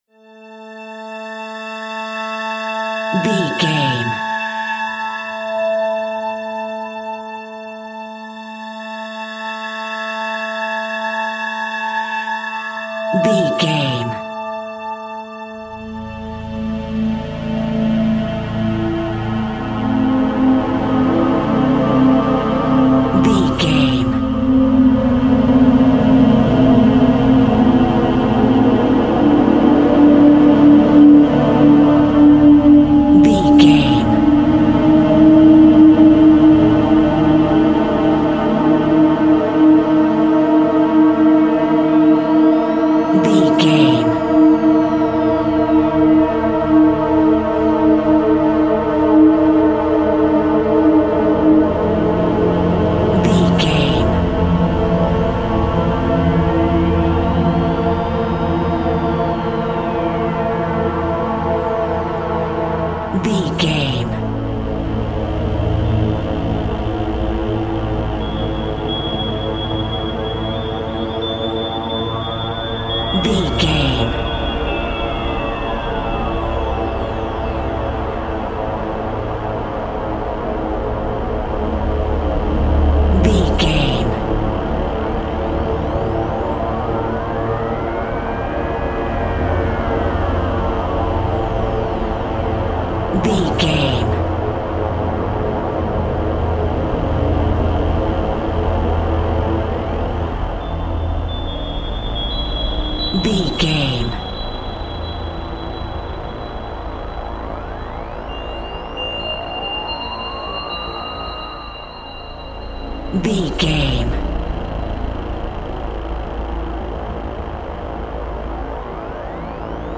Horror Suspense Music Cue.
Aeolian/Minor
ominous
dark
eerie
synthesizer
Horror Pads
horror piano
Horror Synths